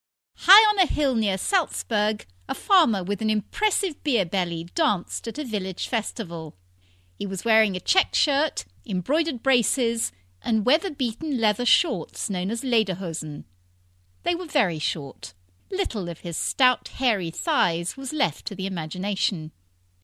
【英音模仿秀】德国传统服饰再次兴起 听力文件下载—在线英语听力室